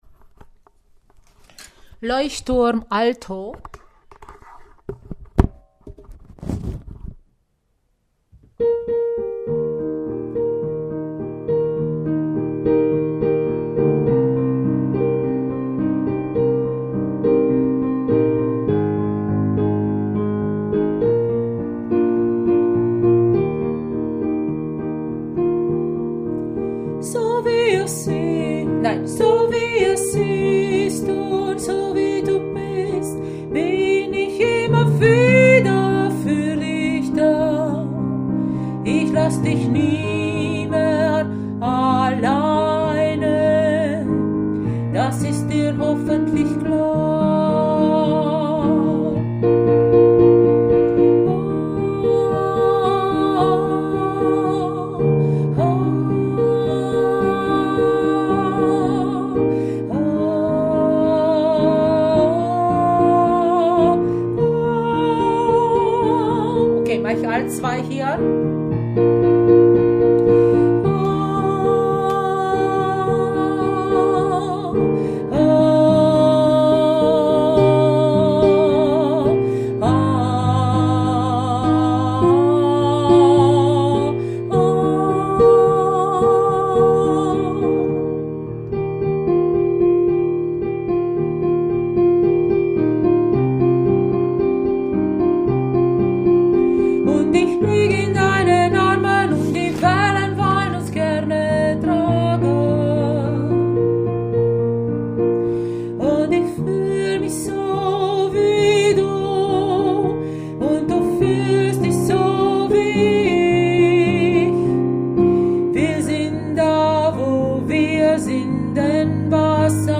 Leuchtturm – Alto